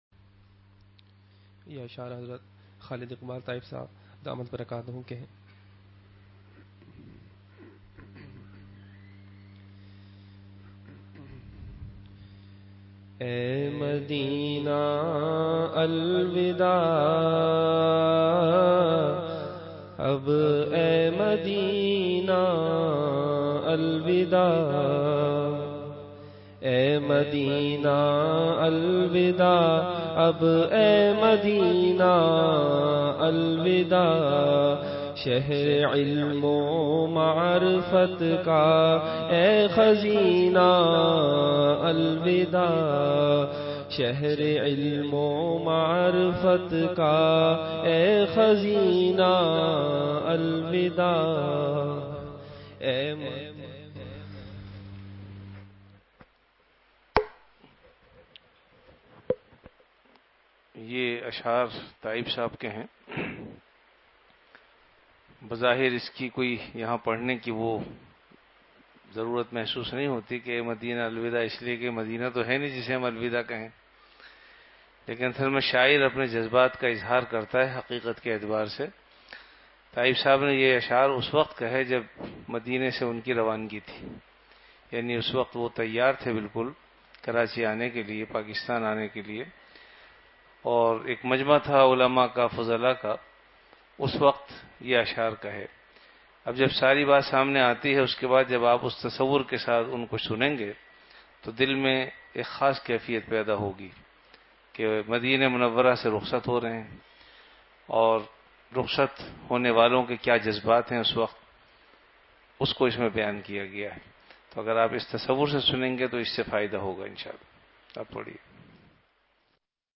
Delivered at Home.
Category Majlis-e-Zikr